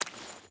Water Chain Walk 5.ogg